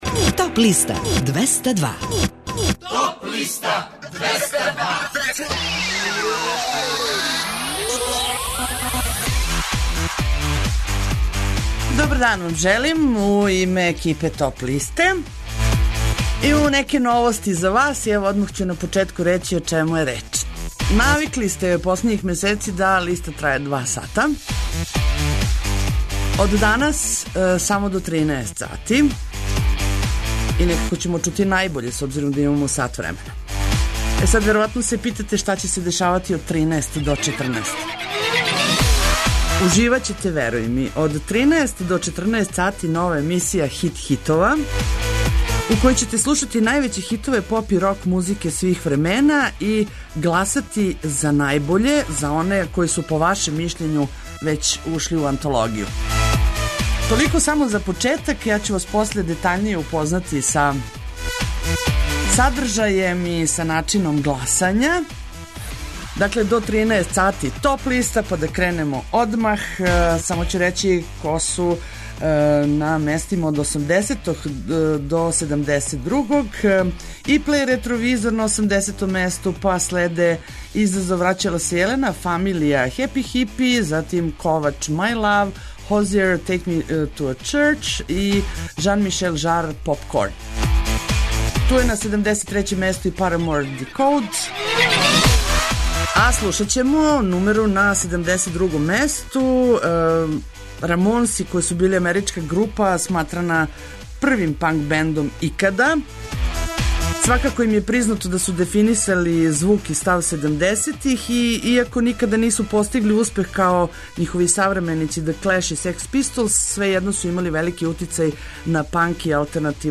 Најавићемо актуелне концерте, подсетићемо се шта се битно десило у историји музике у периоду од 28. марта до 1. априла. Емитоваћемо песме са подлиста лектире, обрада, домаћег и страног рока, филмске и инструменталне музике, попа, етно музике, блуза и џеза, као и класичне музике.